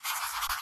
Minecraft Version Minecraft Version snapshot Latest Release | Latest Snapshot snapshot / assets / minecraft / sounds / ui / cartography_table / drawmap2.ogg Compare With Compare With Latest Release | Latest Snapshot
drawmap2.ogg